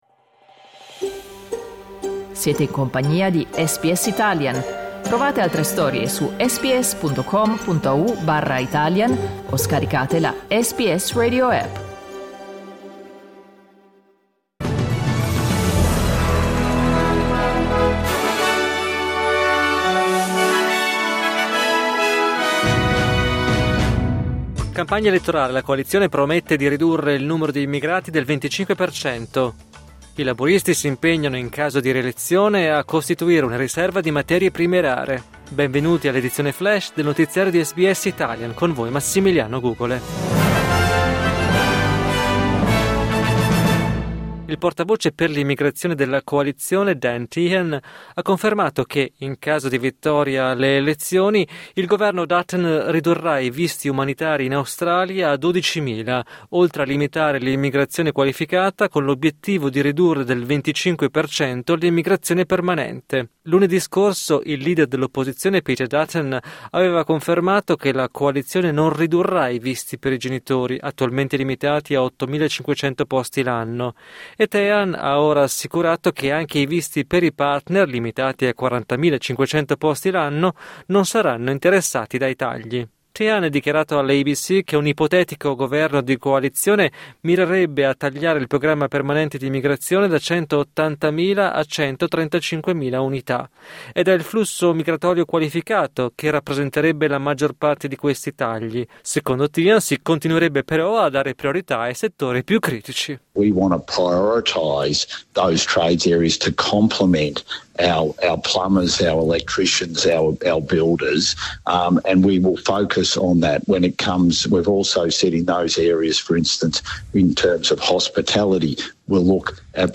News flash giovedì 24 aprile 2025